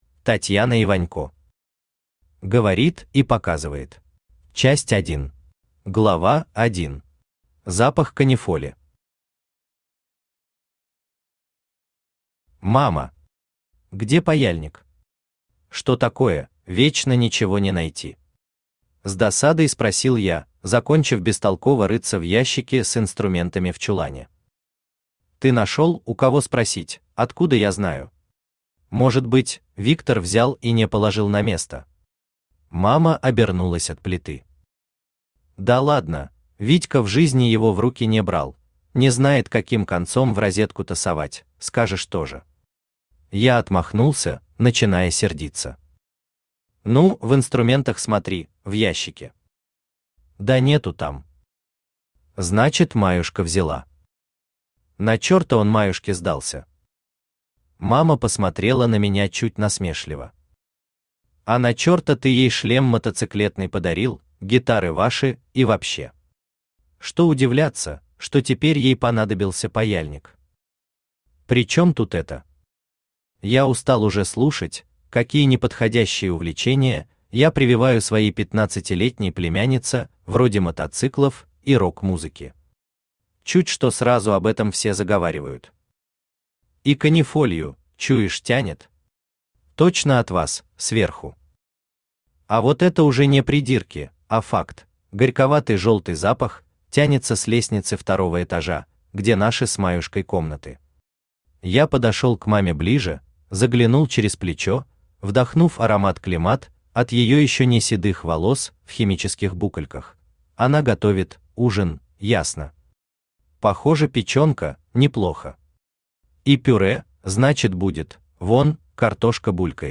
Aудиокнига Говорит и показывает Автор Татьяна Вячеславовна Иванько Читает аудиокнигу Авточтец ЛитРес.